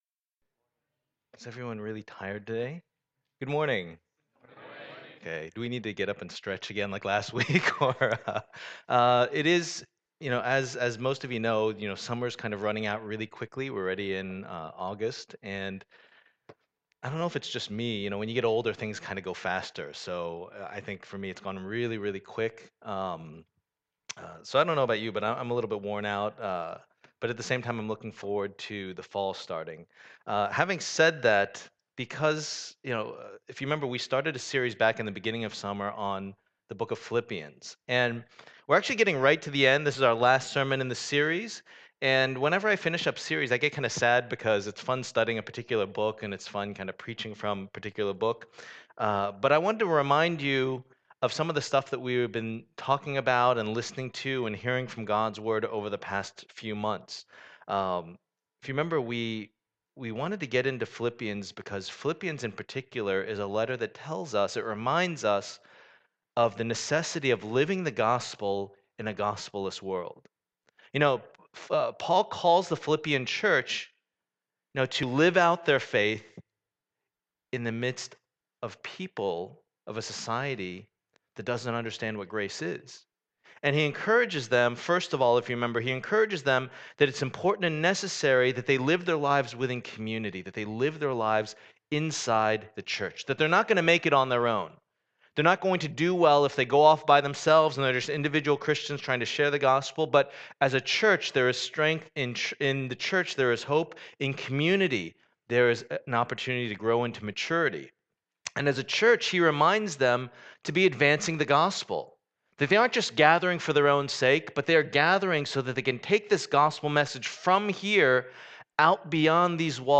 Passage: Philippians 4:14-23 Service Type: Lord's Day